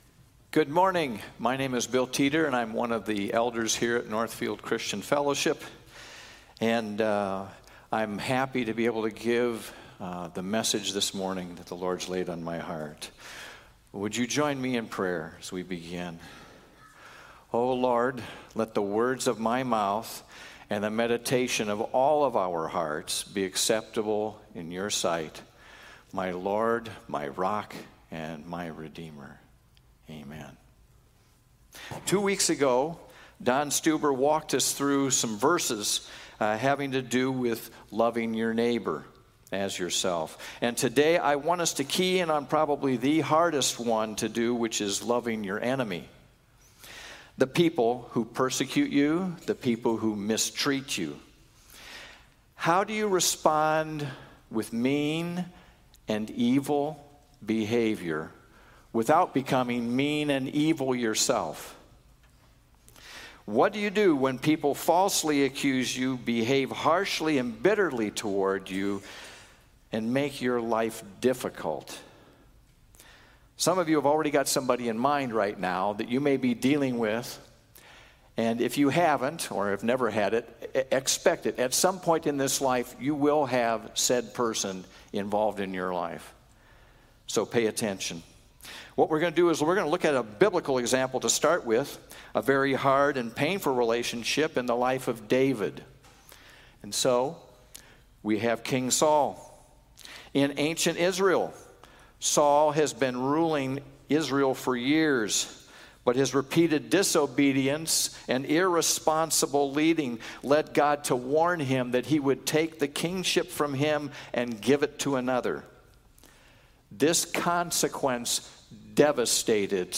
Northfield Christian Fellowship - Sermons Podcast - Bless Those Who Persecute You | Free Listening on Podbean App